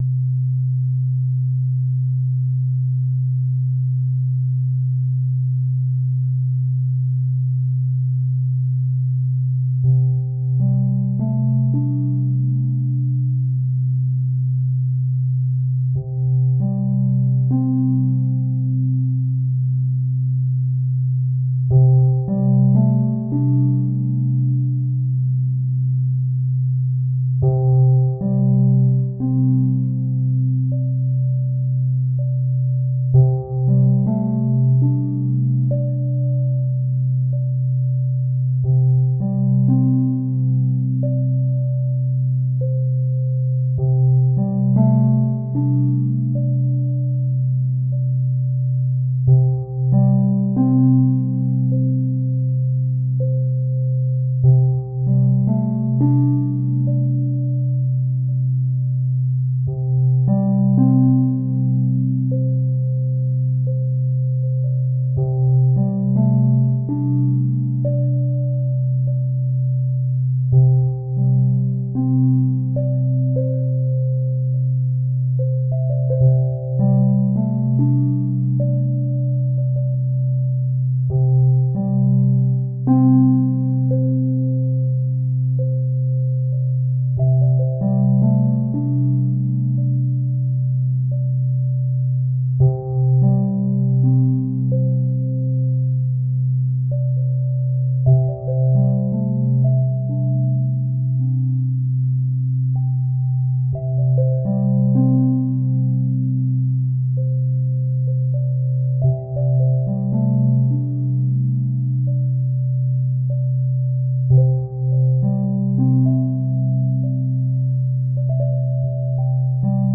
この楽曲を作る上でひとつ浮かんだアイデアが、ドローン(持続音)上にメロディをのせるということでした。
ソフトシンセで合成したC(ド)のドローン(持続音)に、ハ長調のメロディを鳴らす。
今回もあまり抑揚のない、シンプルな展開に収めました。